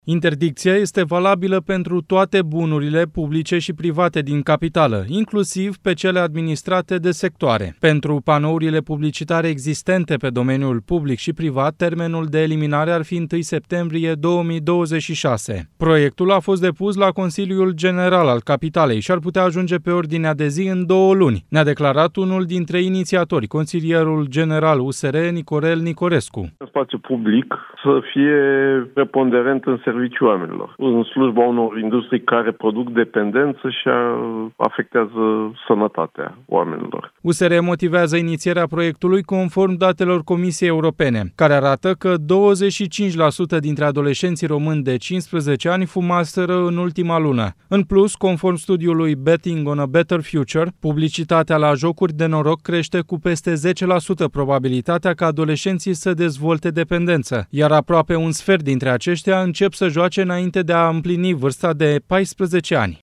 Proiectul a fost depus la Consiliul General al Capitalei și ar putea ajunge pe ordinea de zi în două luni, a declarat pentru Europa FM unul dintre inițiatori, consilierul general USR Nicorel Nicorescu.
„Spațiul public să fie preponderent în serviciul oamenilor, nu în slujba unor industrii care produc dependență și afectează sănătatea oamenilor”, a spus consilierul general USR Nicorel Nicorescu.